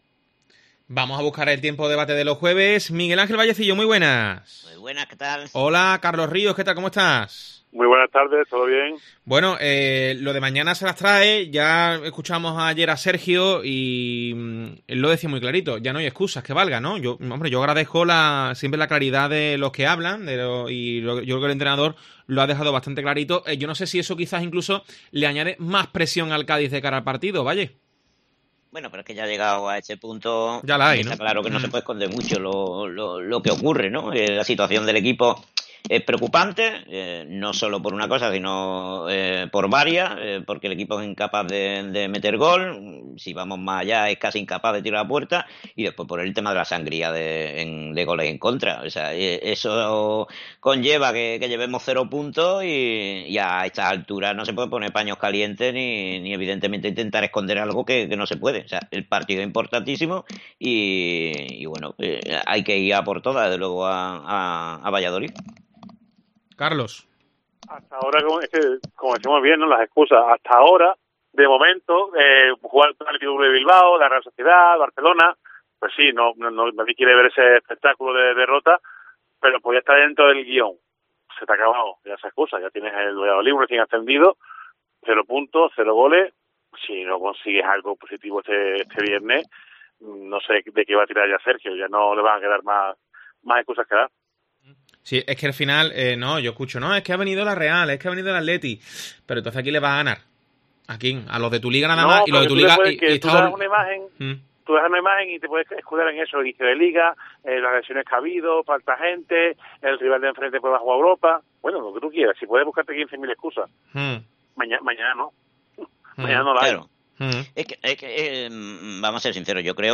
El debate de Deportes COPE sobre el Cádiz CF